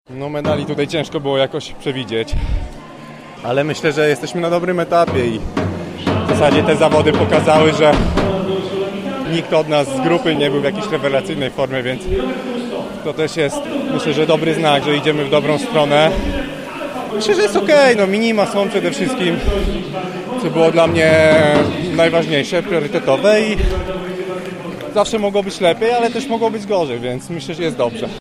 Mówi Konrad Czerniak.